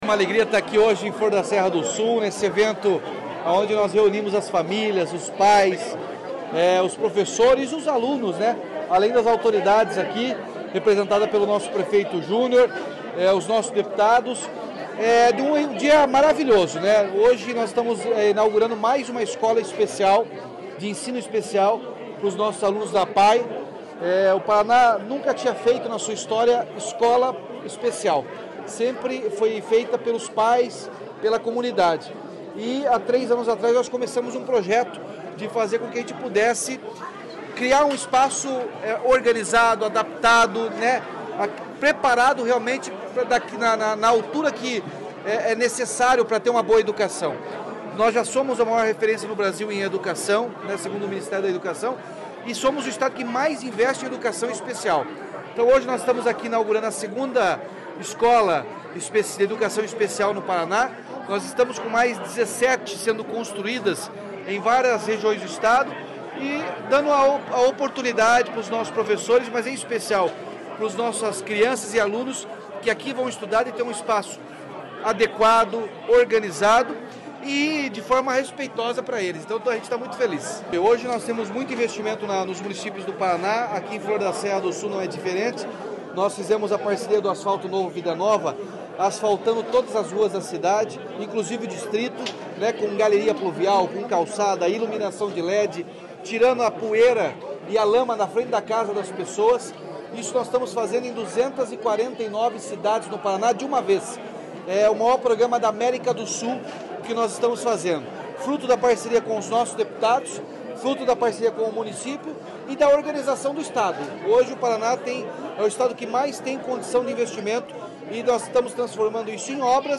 Sonora do governador Ratinho Junior sobre a nova APAE de Flor da Serra do Sul